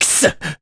Mitra-Vox_Damage_jp_01.wav